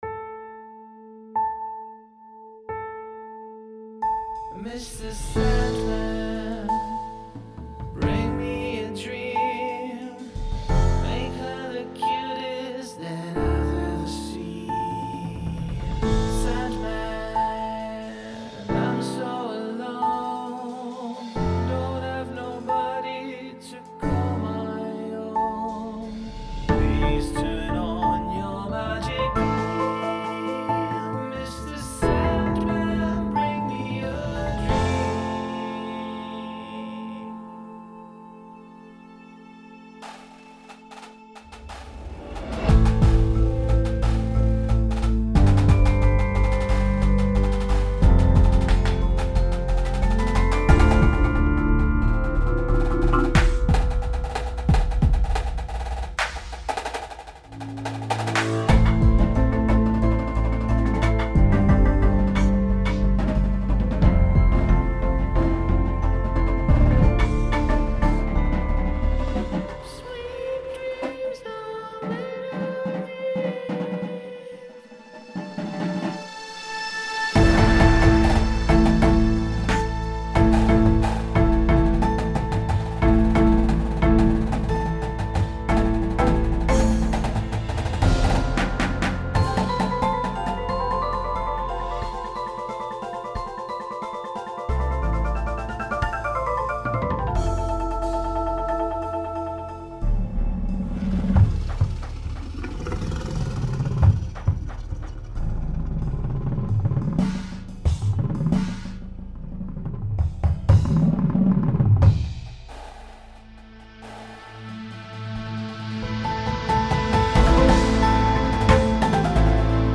Price: $1,200.00 Length: 5:45 Indoor Difficulty: Open Class